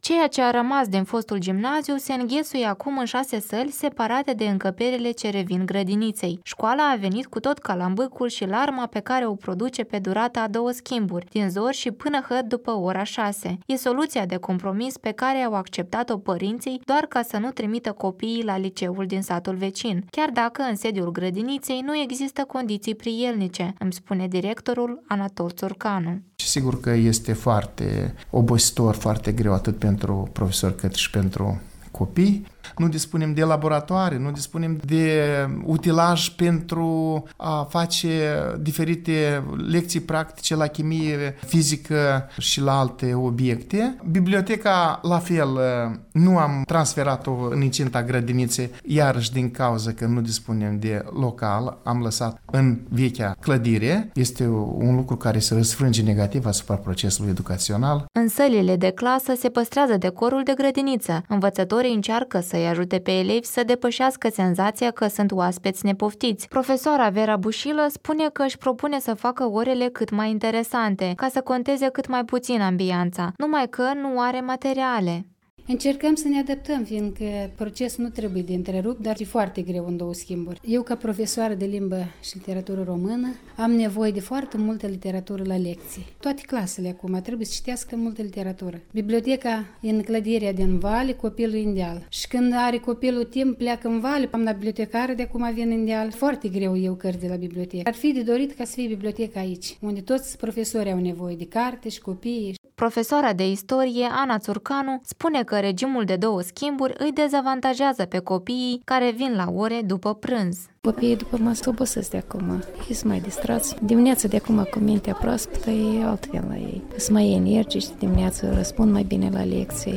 Reportaj de la şcoala din Cigârleni